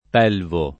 [ p $ lvo ]